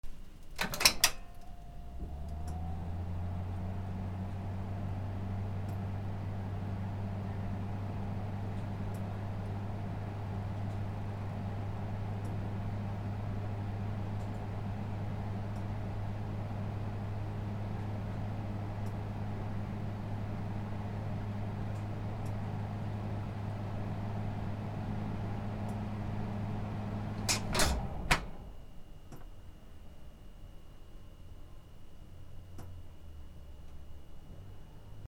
換気扇
/ M｜他分類 / L10 ｜電化製品・機械